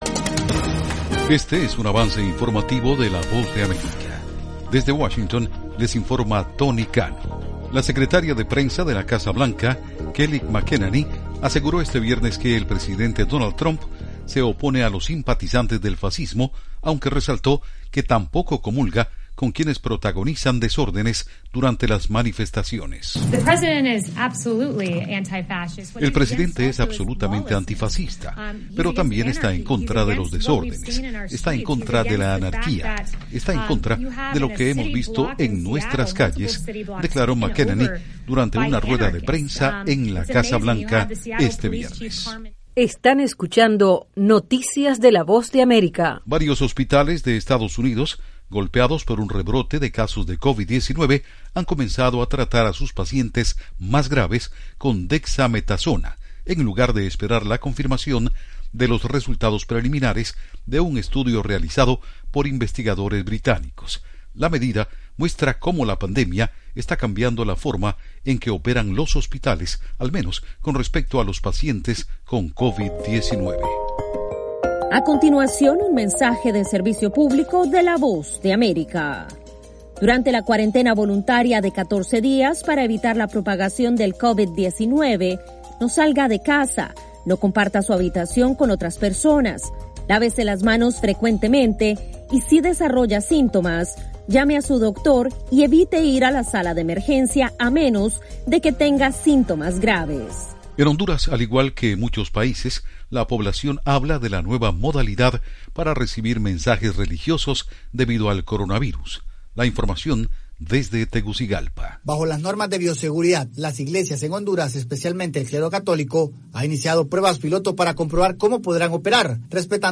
Algunas de las noticias de la Voz de América en este avance informativo: